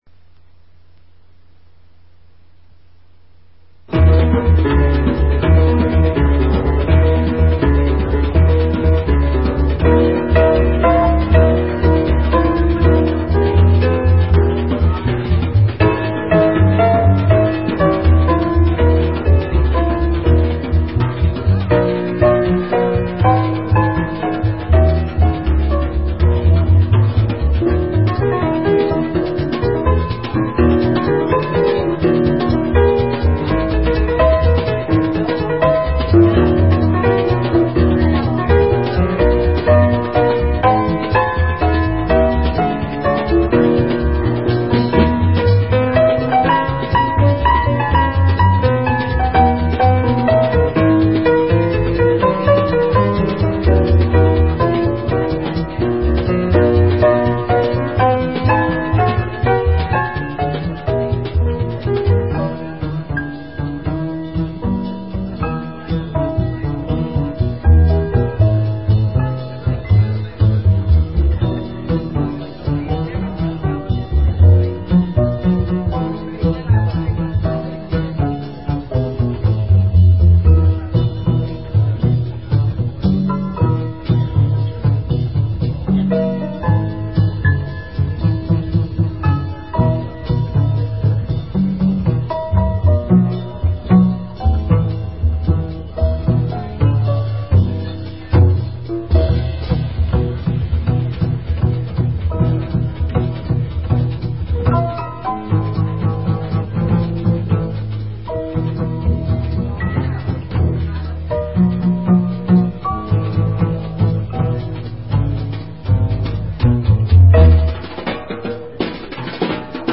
piano
bass
drums
These selections are taken from a pre-production tape.